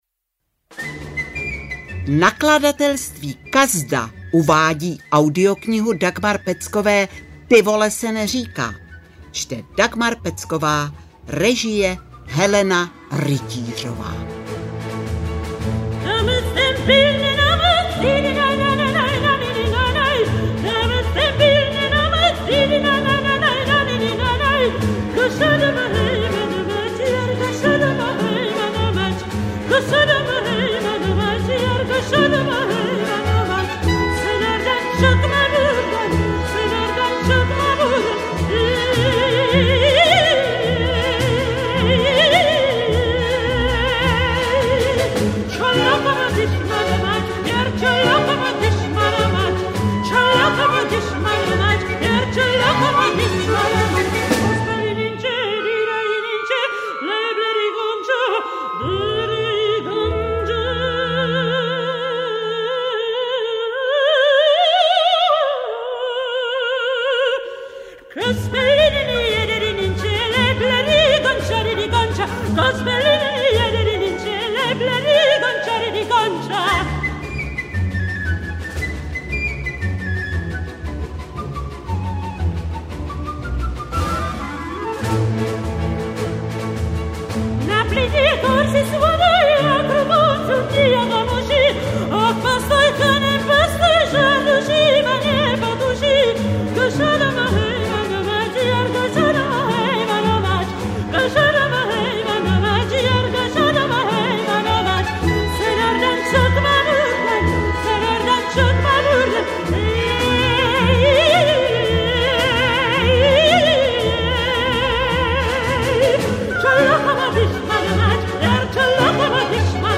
Ty vole se neříká audiokniha
Ukázka z knihy